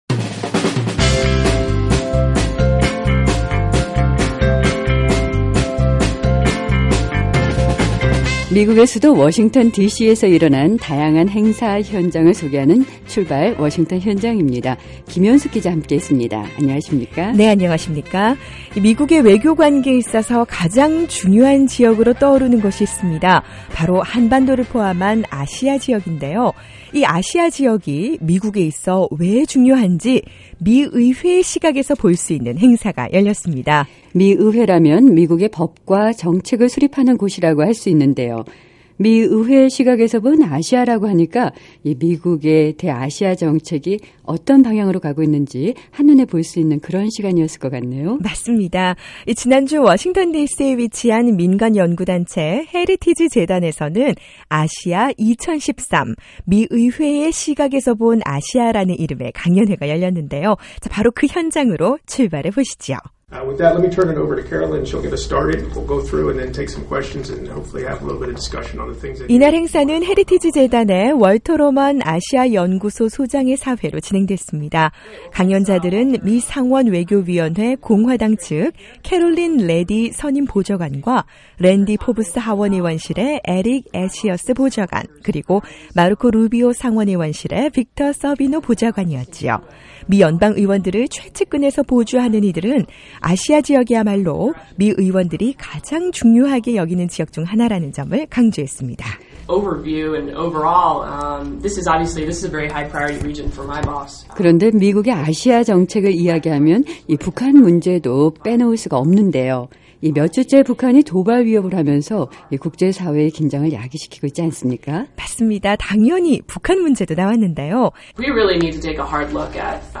워싱턴 디씨에서는 ‘미의회의 시각에서 보는 아시아’라는 주제의 강연회가 열렸습니다. 미의회 보좌관들과 직원들이 함께해 강연과 토론을 이어간 현장, 당연히 북한에 대한 언급도 있었는데요 행사가 열렸던 헤리티지 재단으로 출발해보시죠!